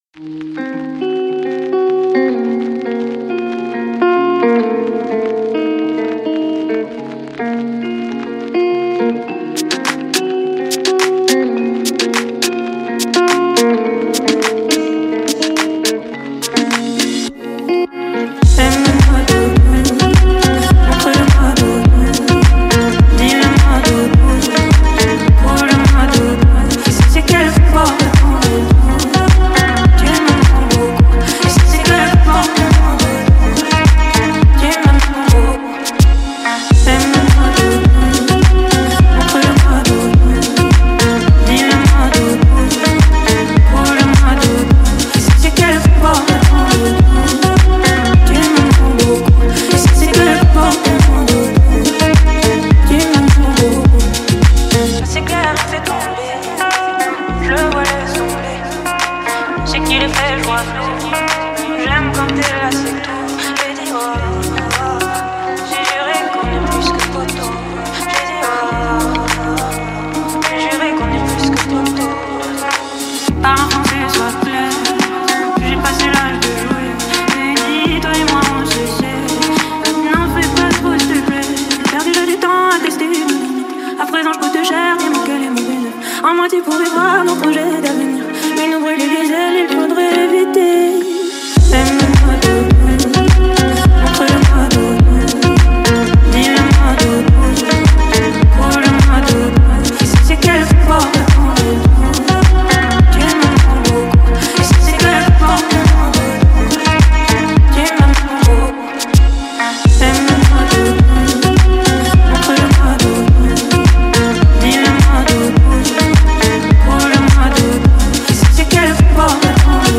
а электронные элементы добавляют современности.